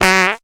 因此，在加入背景音的情况下“噗噗噗泡泡”的音效会相对清晰和明显
“噗噗噗泡泡”魔性音效>>>
BombExplosion013.ogg